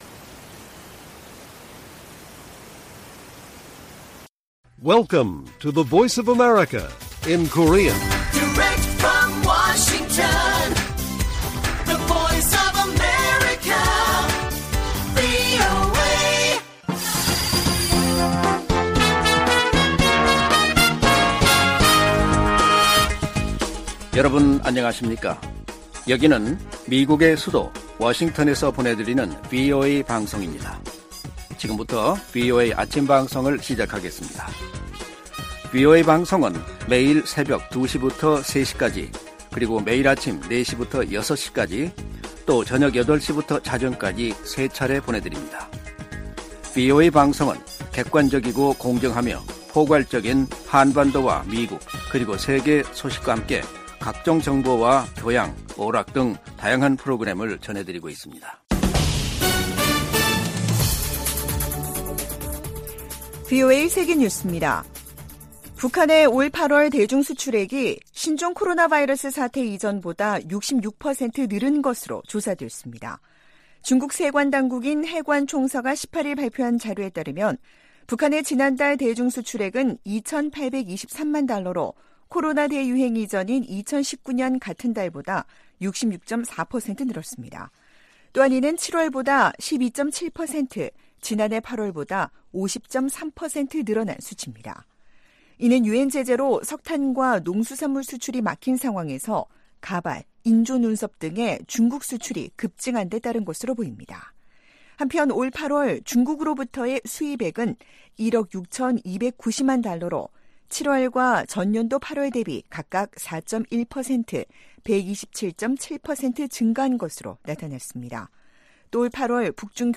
세계 뉴스와 함께 미국의 모든 것을 소개하는 '생방송 여기는 워싱턴입니다', 2023년 9월 19일 아침 방송입니다. '지구촌 오늘'에서는 미국과 이란 간 수감자 맞교환이 18일 이뤄진다고 이란 측이 밝힌 가운데 미국인들을 태운 비행기가 출발한 것으로 알려진 소식 전해드리고, '아메리카 나우'에서는 케빈 매카시 하원의장이 지난주 조 바이든 대통령 탄핵 조사를 지시한 가운데, 차남 헌터 바이든 씨를 조사에 소환하게 될 것이라고 말한 이야기 살펴보겠습니다.